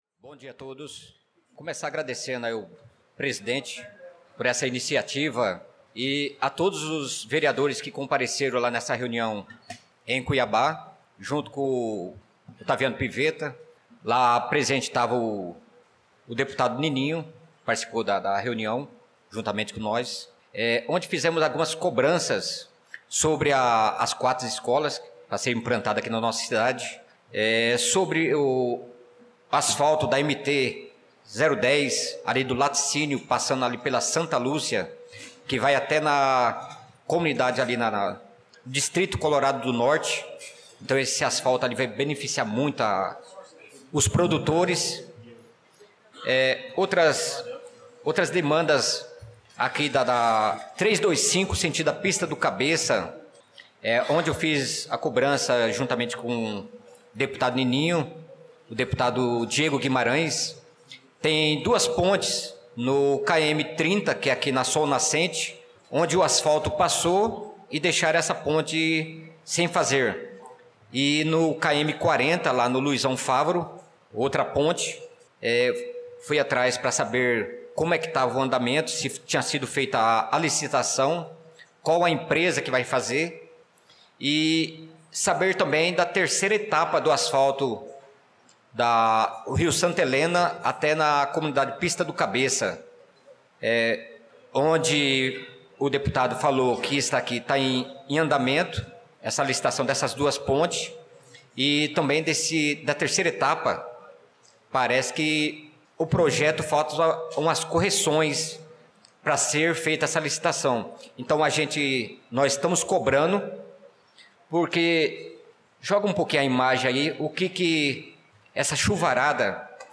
Pronunciamento do vereador Naldo da Pista na Sessão Ordinária do dia 18/02/2025